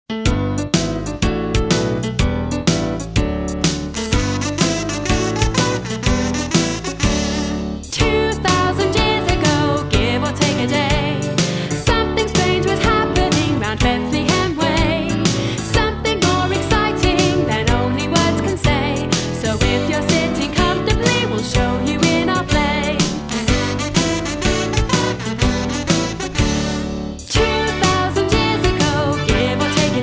CD (Vocals and Backing Tracks)